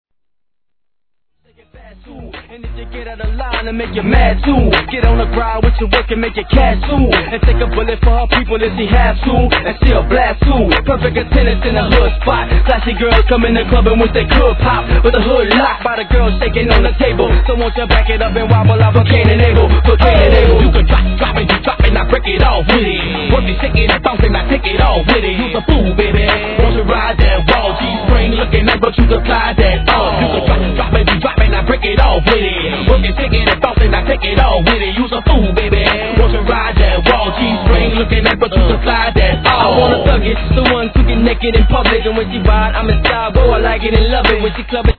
G-RAP/WEST COAST/SOUTH
アッパーなBOUNCEビートでさすがのコンビーネーションを披露!!